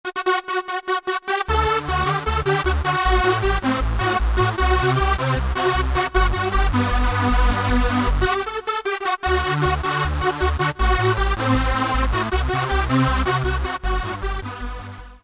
107 Euro-Dance 1
Typical '90s Euro saw.